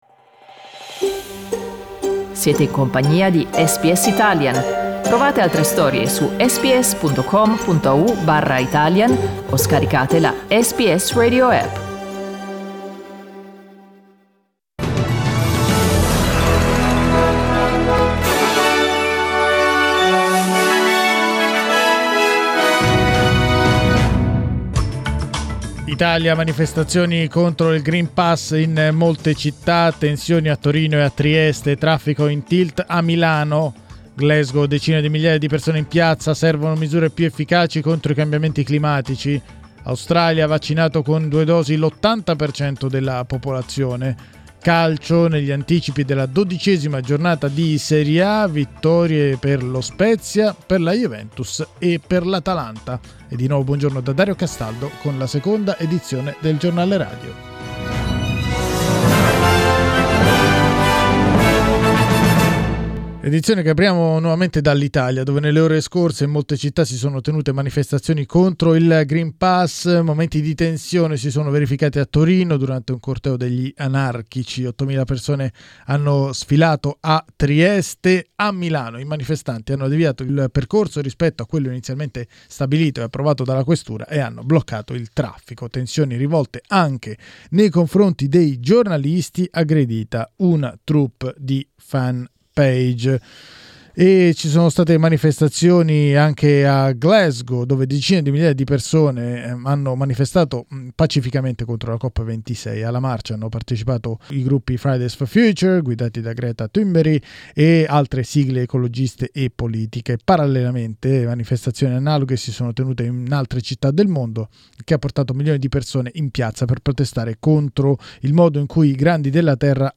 Giornale radio domenica 7 novembre 2021
Il notiziario di SBS in italiano.